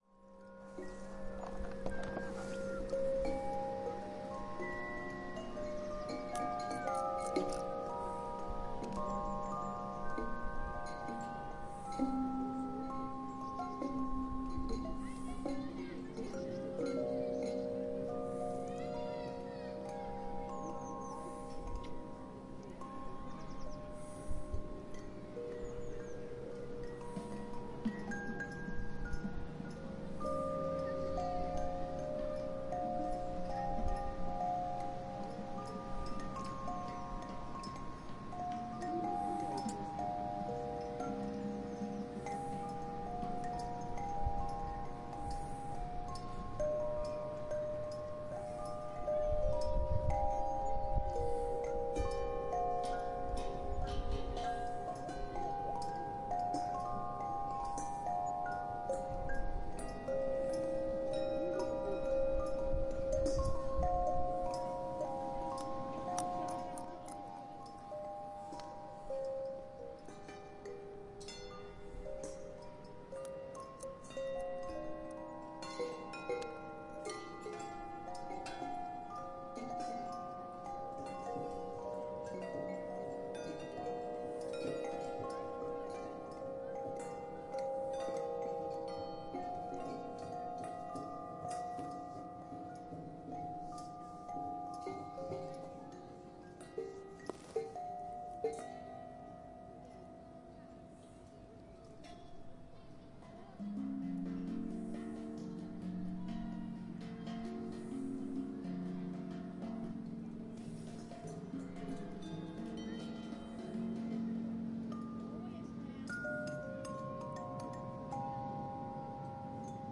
gong
描述：mediumsize gong
标签： instruments musical
声道立体声